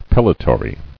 [pel·li·to·ry]